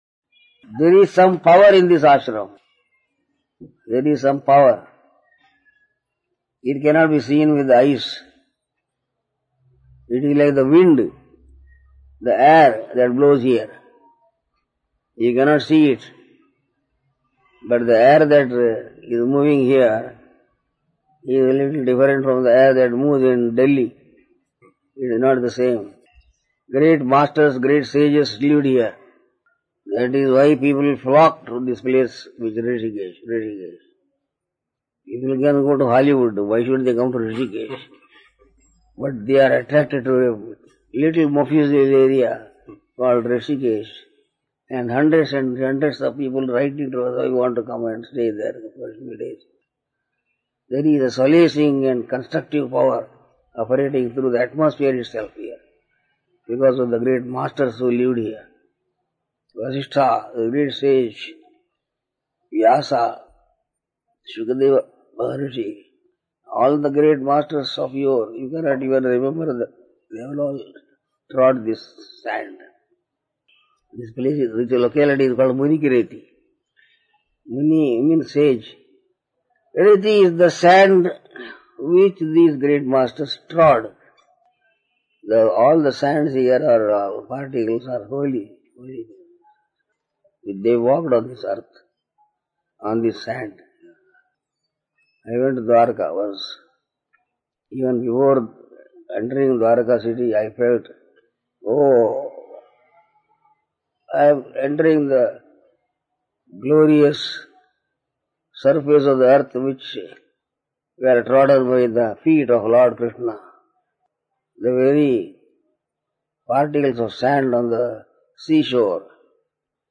(Spoken to a group of guests and visitors on January 16th, 1999)